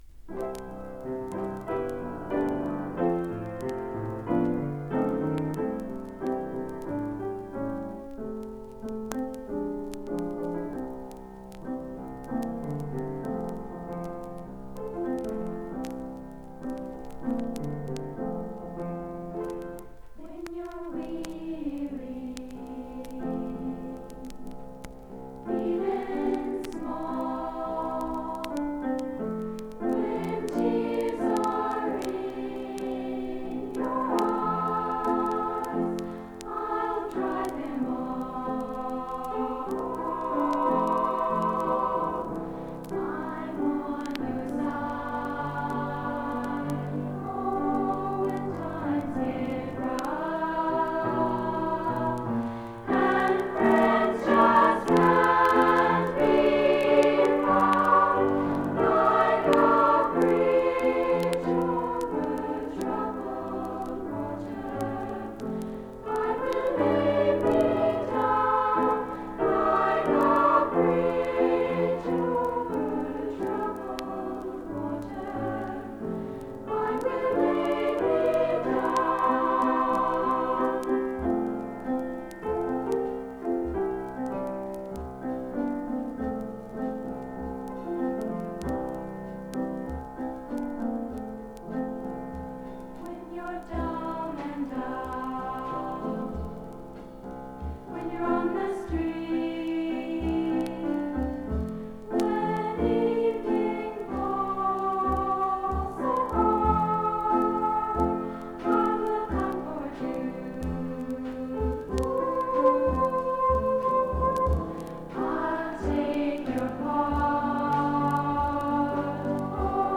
For a female choir, they're decent.